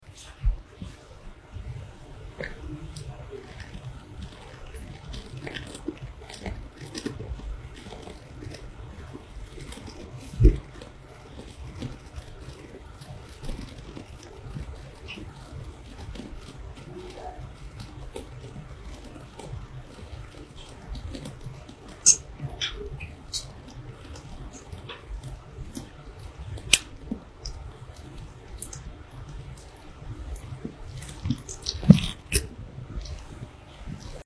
Cutting Brush with Hot Knife sound effects free download
Cutting Brush with Hot Knife ASMR